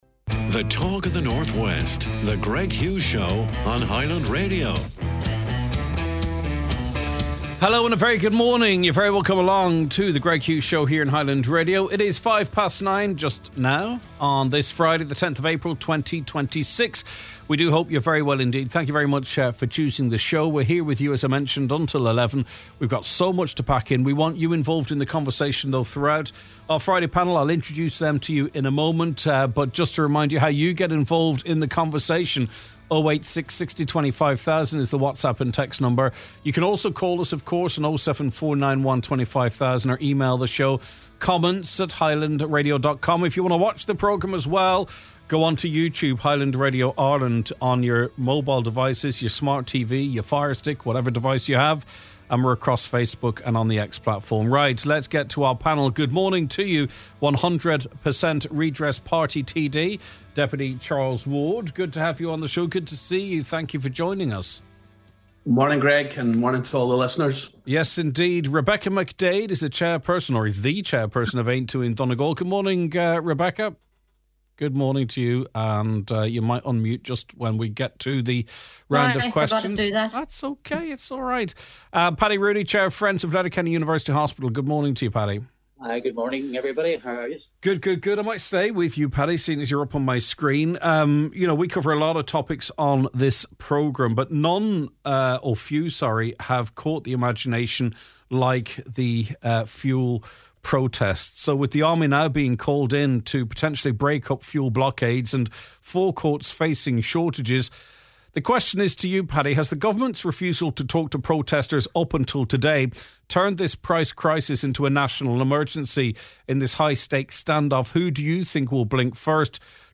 The Friday Panel: Fuel Hikes & Public Defiance